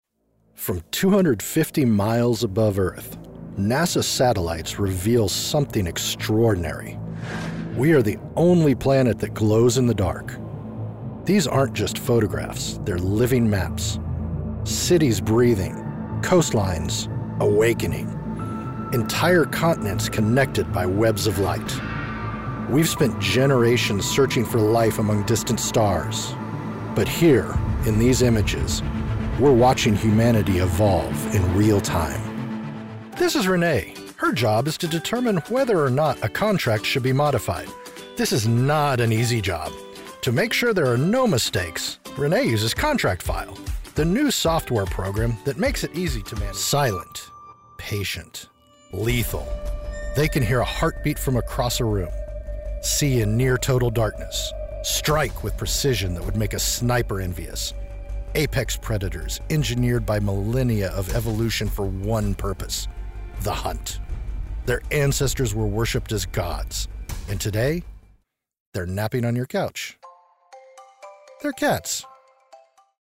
Male
English (North American)
Adult (30-50), Older Sound (50+)
My voice has been described as resonant, soothing, natural, authoritative, and robust - I inspire trust in your message and connection with your customers.
Narration Samples